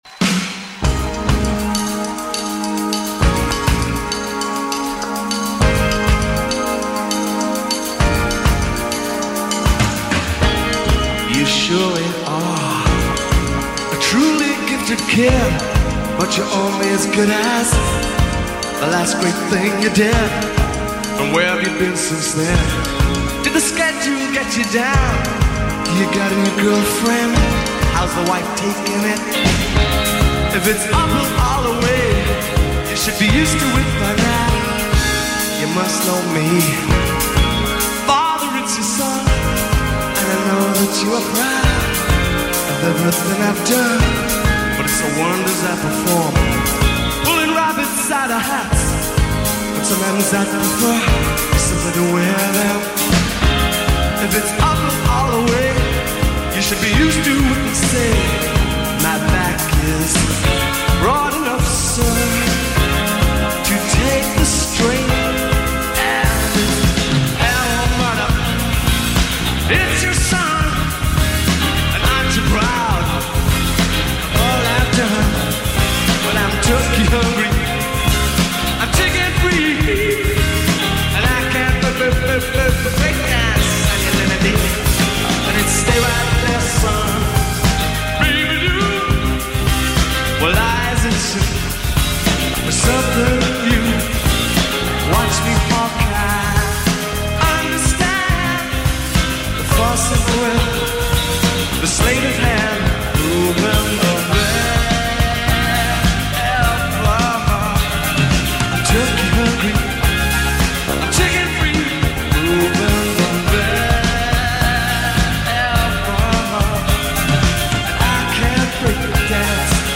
drummer
With emphasis on New Wave, Sophisti-Pop and Jazz Pop